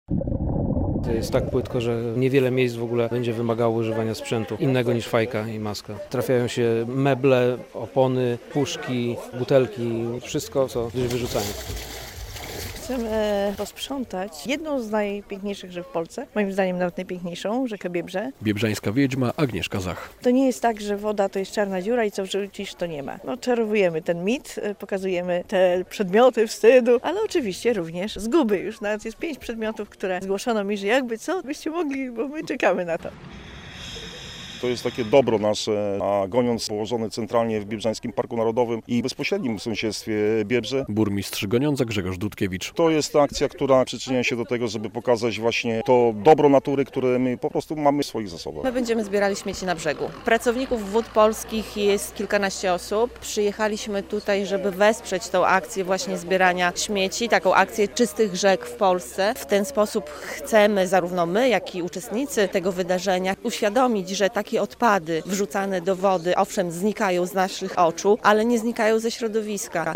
Sprzątanie Biebrzy - relacja